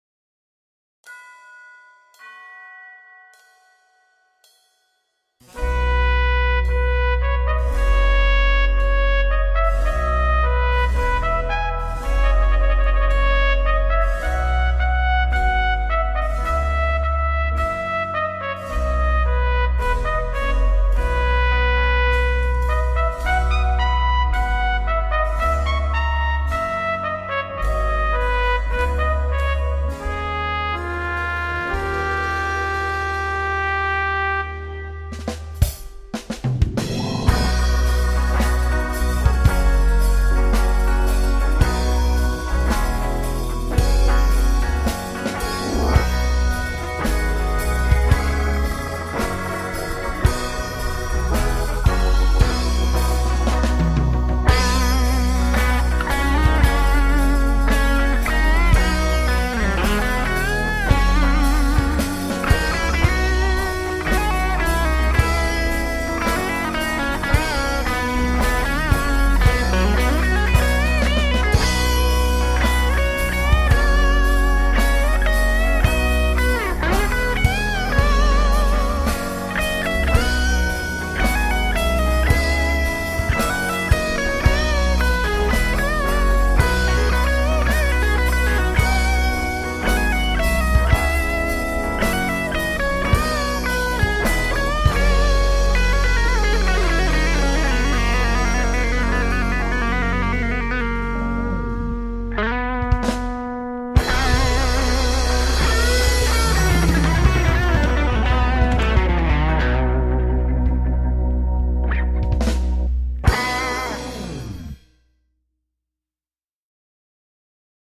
• Жанр: Детские песни
🎶 Детские песни / Песни из мультфильмов